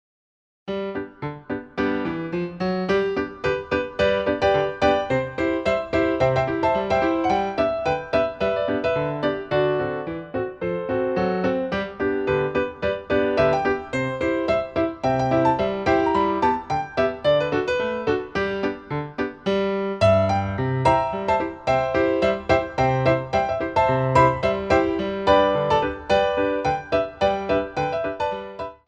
WARMUP JUMP